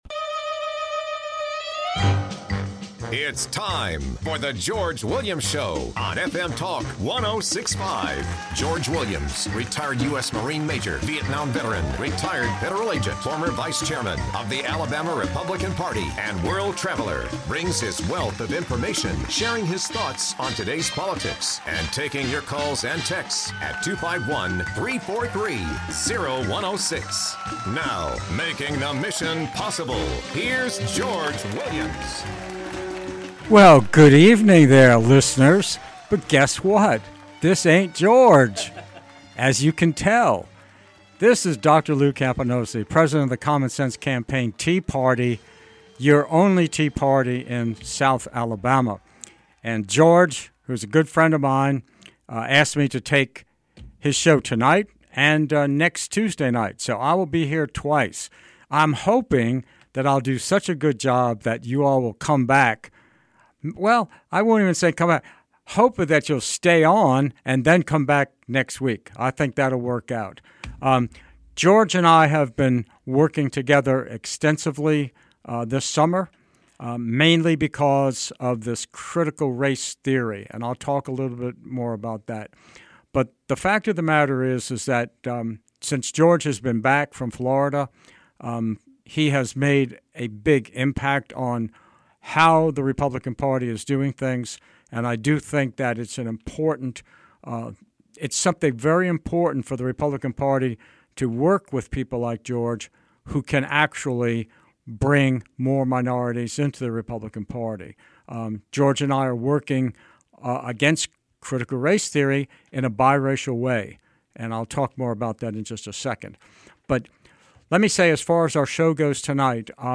phone guests